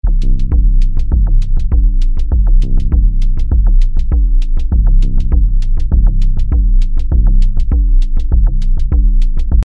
In einem ersten Versuch habe ich eine Sequenz aus meinem Nord Lead 4 genommen: